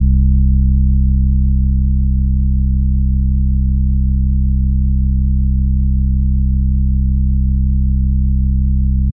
Classic Sub 808.wav